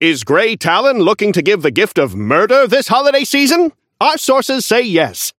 Newscaster voice line - Is Grey Talon looking to give the gift of murder this holiday season?
Newscaster_seasonal_orion_unlock_01.mp3